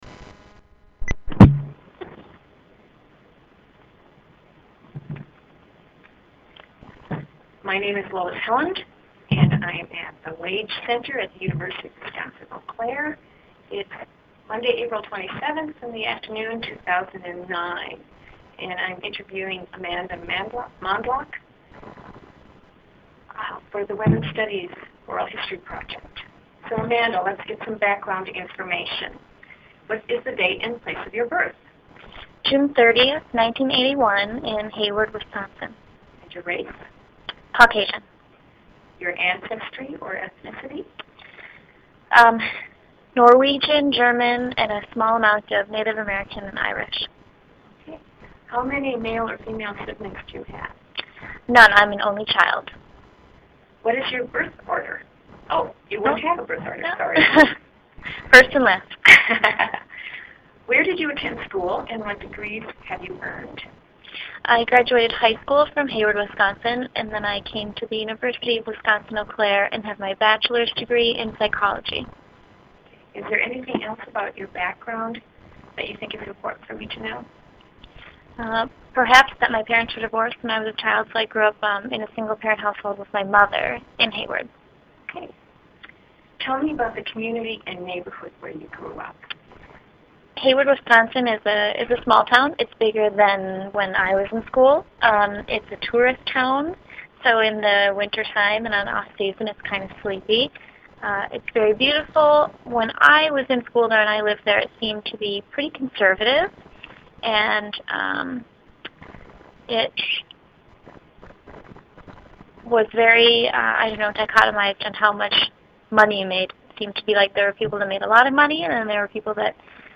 This interview is part of an oral history project conducted in honor of 25th anniversary of the Women's Studies Program at the University of Wisconsin - Eau Claire.